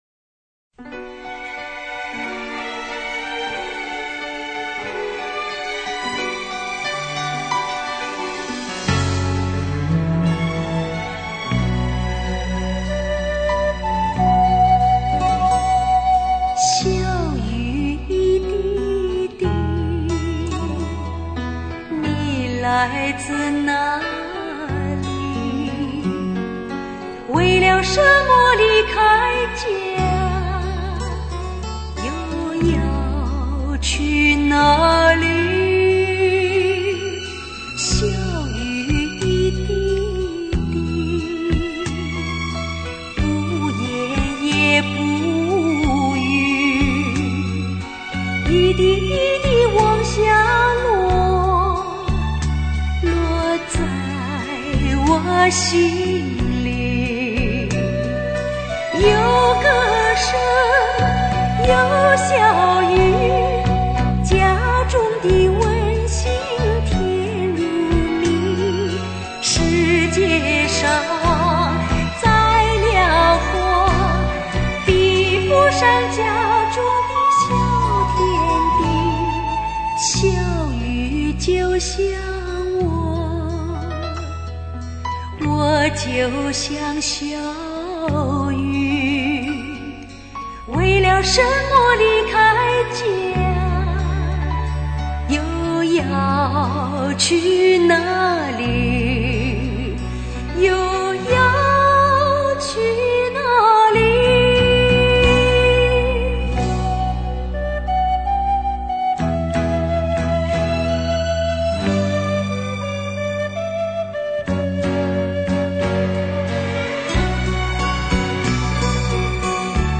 淡淡的忧伤